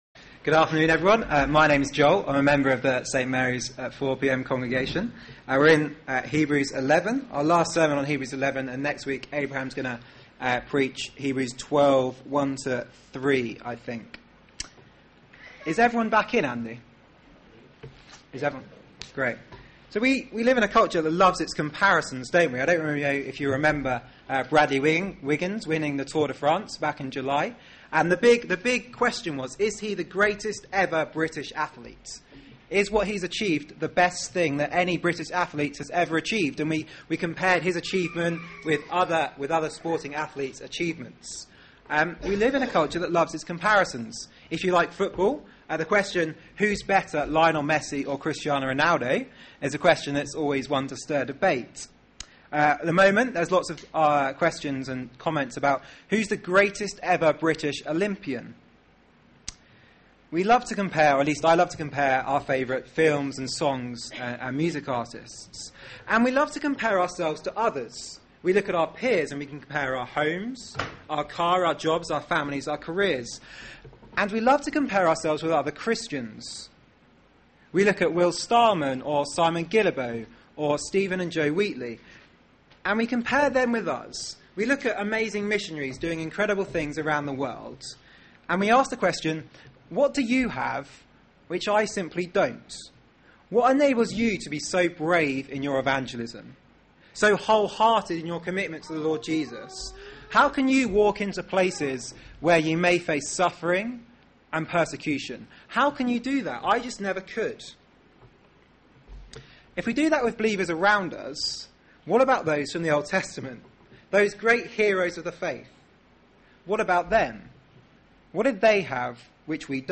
Media for 4pm Service on Sun 19th Aug 2012 16:00 Speaker
Sermon